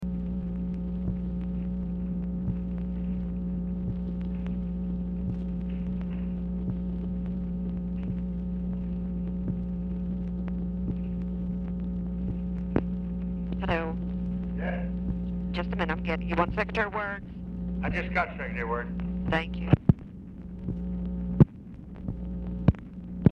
Telephone conversation # 10783, sound recording, LBJ and TELEPHONE OPERATOR, 9/16/1966, time unknown | Discover LBJ
Format Dictation belt
Location Of Speaker 1 Oval Office or unknown location
Specific Item Type Telephone conversation